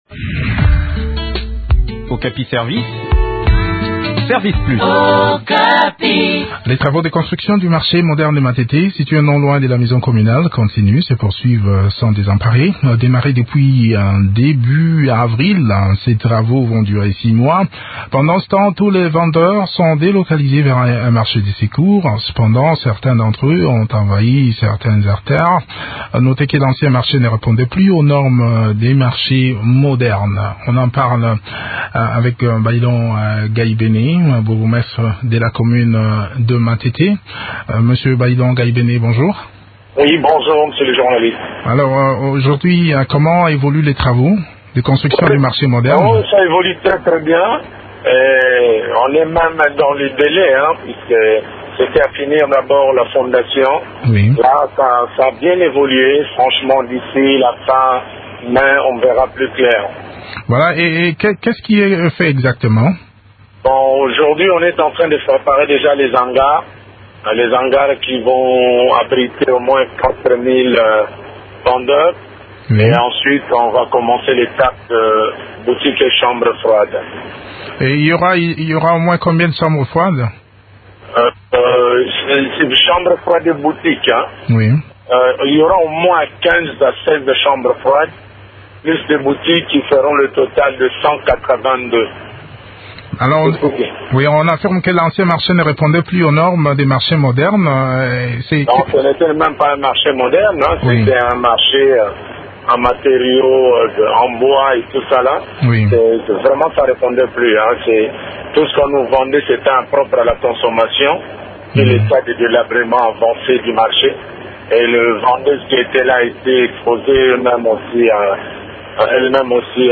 s’entrtetient sur le sujet avec Baylon Gaibene, bourgmestre de la commune de Matete.